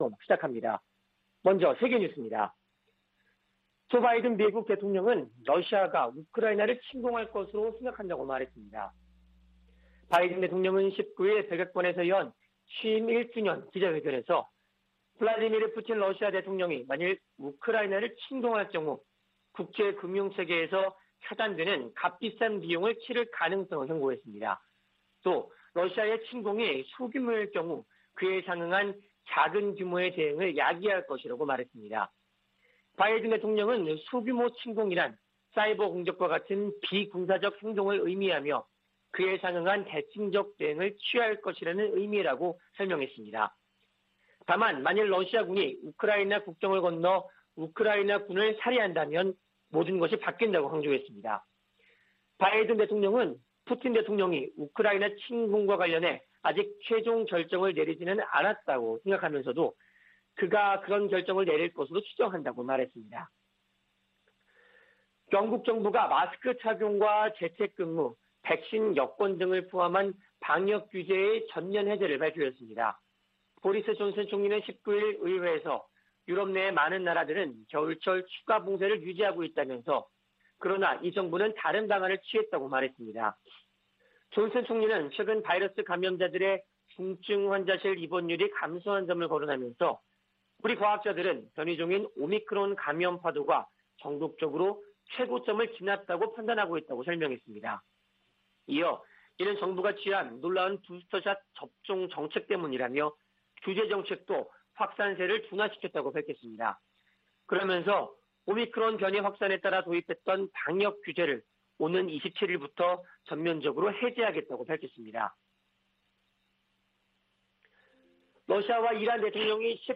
VOA 한국어 '출발 뉴스 쇼', 2021년 1월 21일 방송입니다. 북한이 핵과 ICBM 시험 등의 유예를 철회할 것을 시사하며 대미 압박을 강화하고 있습니다. 미 공화당 중진 상원의원이 북한의 미사일 시험에 대해 핵전쟁 승리를 위한 전술무기 개발 목적이라고 경고했습니다. 유럽연합(EU)이 유엔 안보리 긴급 회의 개최와 관련해 북한의 대량살상무기(WMD) 확산 방지를 위해 노력할 것이라는 점을 재확인했습니다.